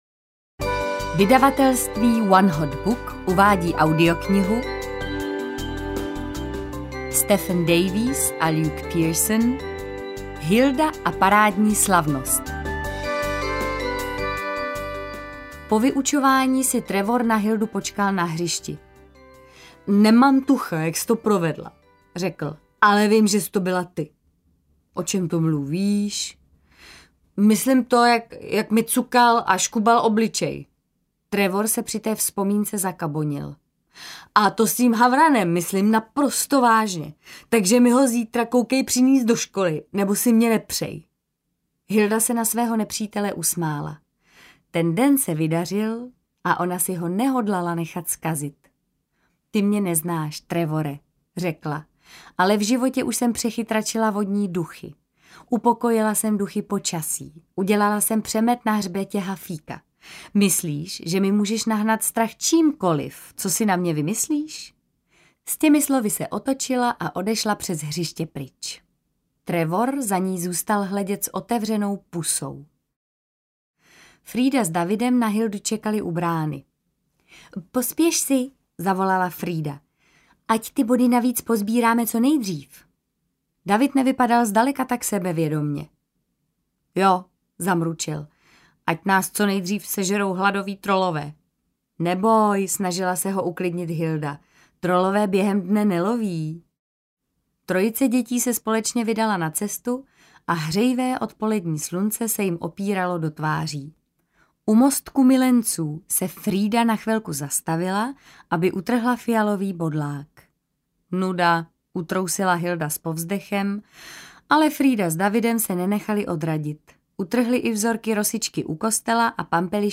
Hilda a parádní slavnost audiokniha
Ukázka z knihy